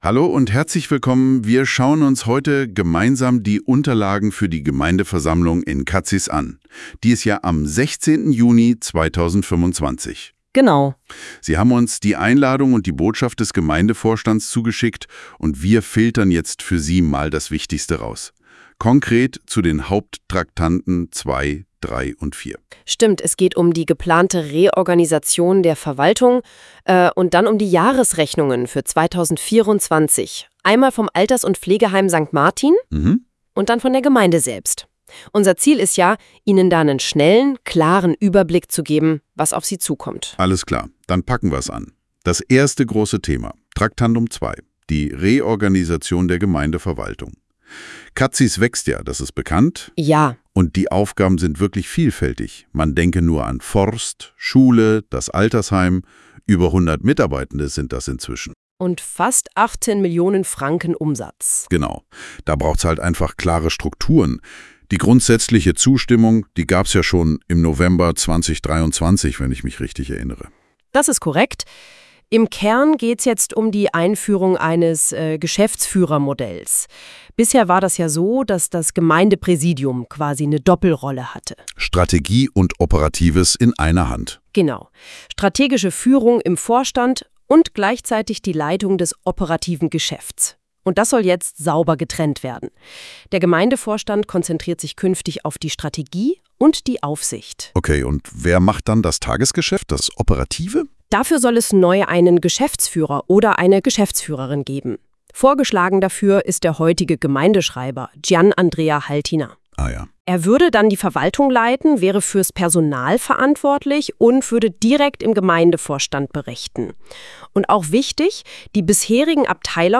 KI-Experiment: Die Botschaft als Podcast.